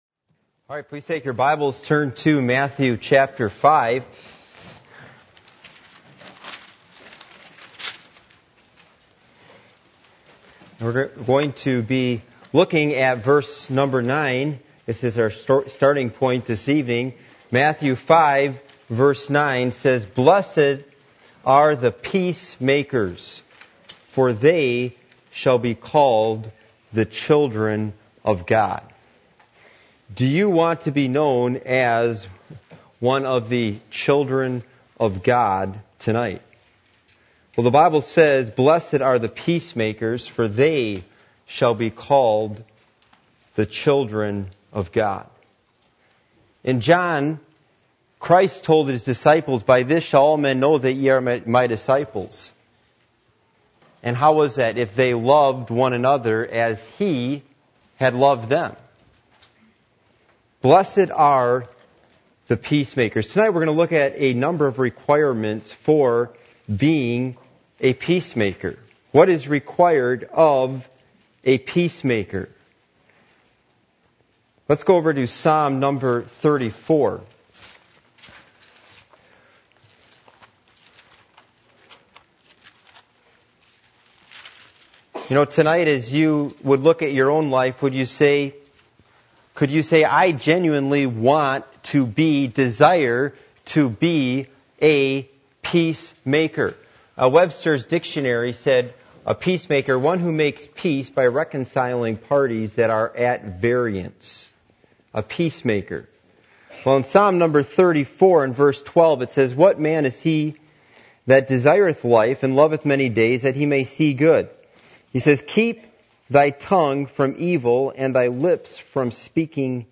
Passage: Matthew 5:8-9 Service Type: Midweek Meeting %todo_render% « Doing That